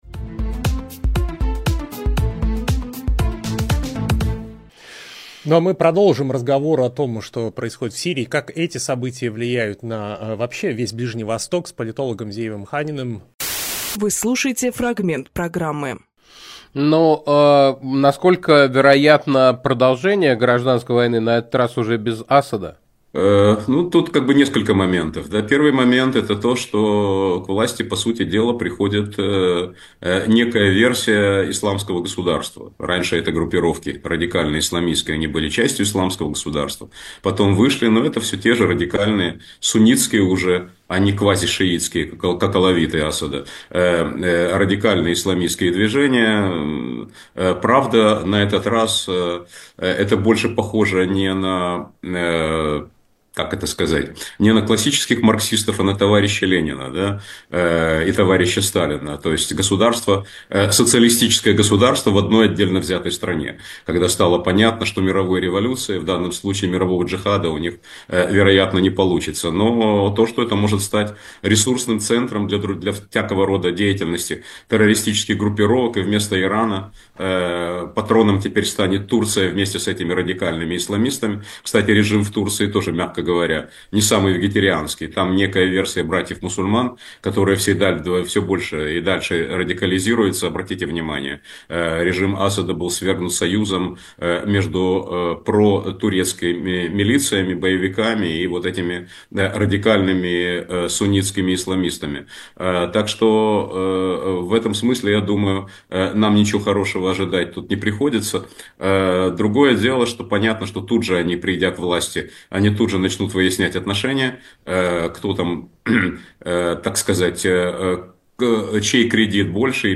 Фрагмент эфира от 08.12.24